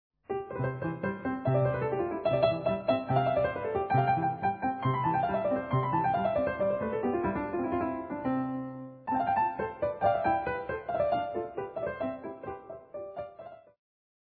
Je les ai enregistrés sur mon clavier numérique Roland RD700 (ce ne sont pas des fichiers midi).